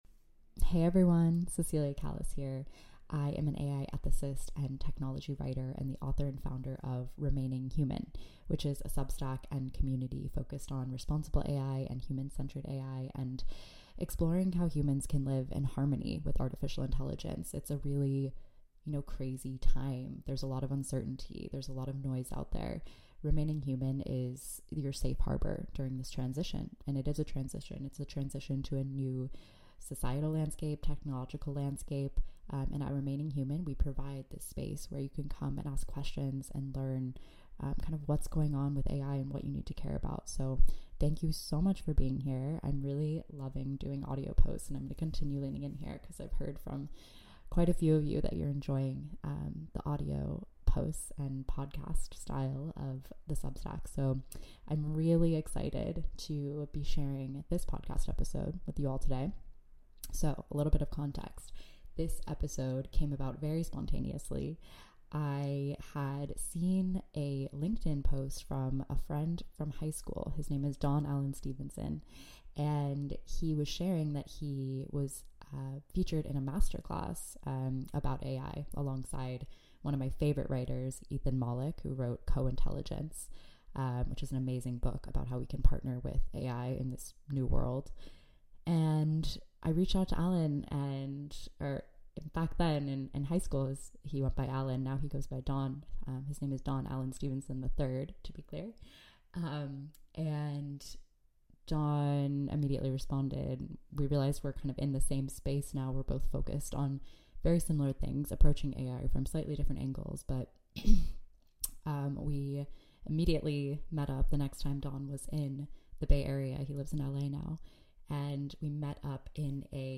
podcast conversation with renowned creative technologist